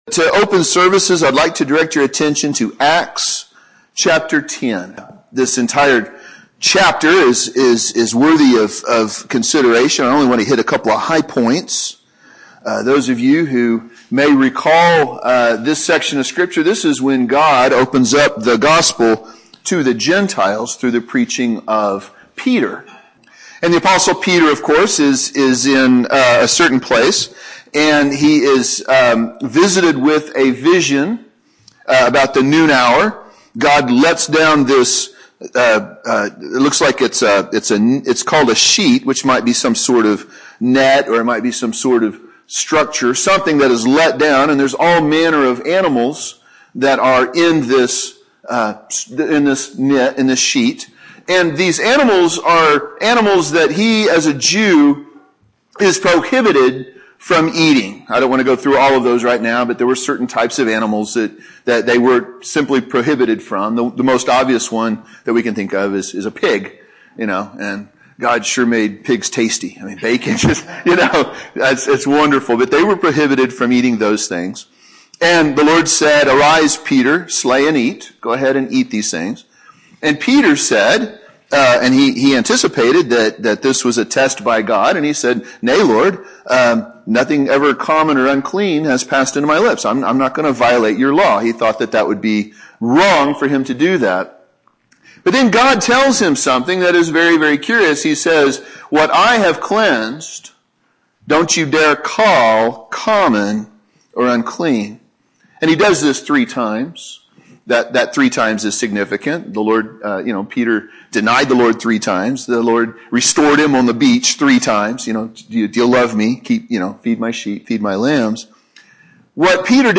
Sermons preached in other churches • Page 23
This sermon was recorded at Walnut Valley Primitive Baptist Church Located in Blanchard, Oklahoma